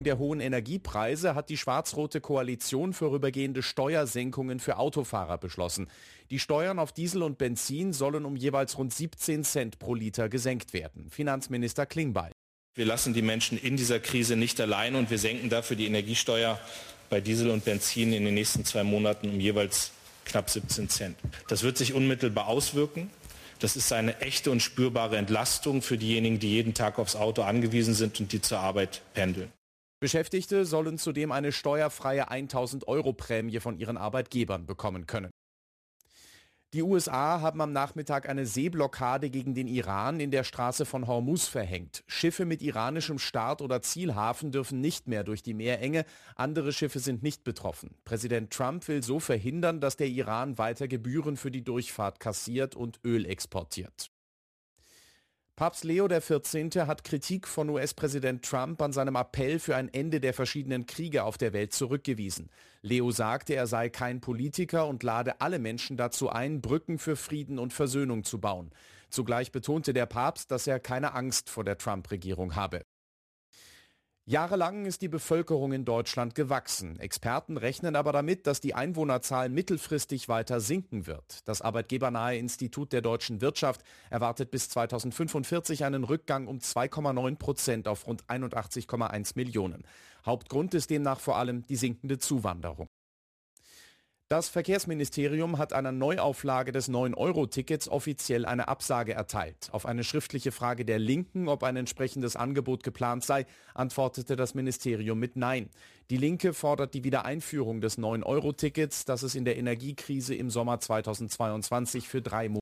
Nachrichten , Nachrichten & Politik
Die aktuellen Nachrichten zum Nachhören